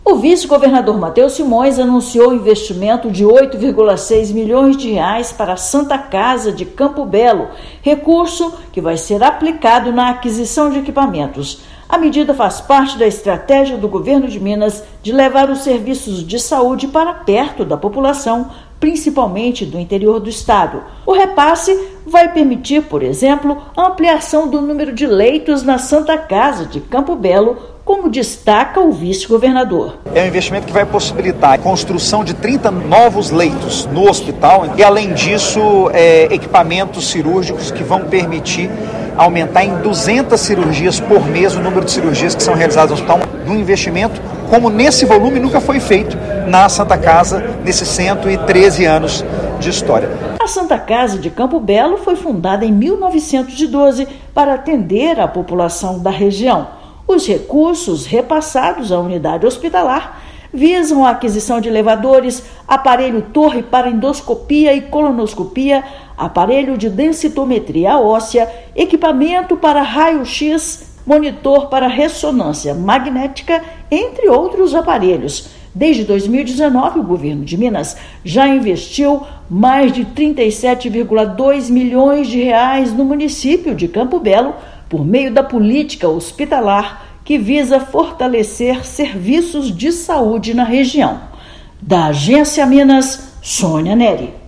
Repasse permitirá melhores condições para o hospital e a abertura de novos leitos. Ouça matéria de rádio.